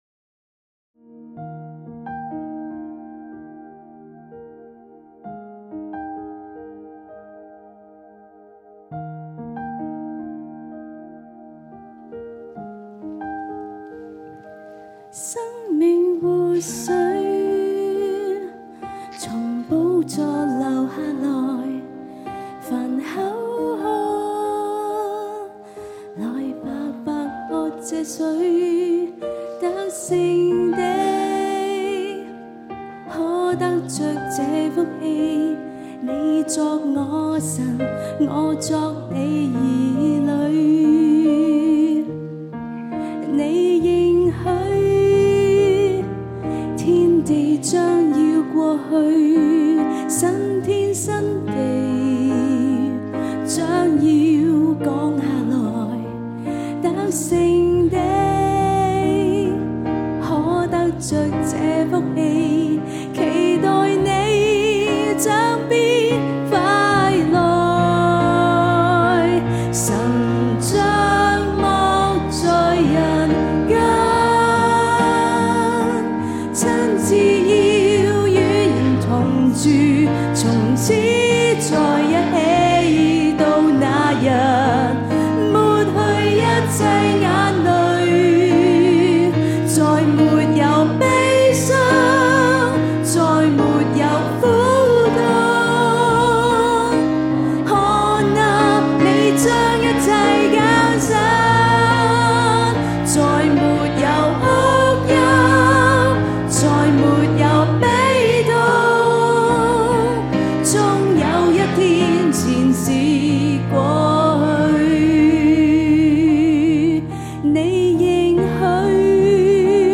現場敬拜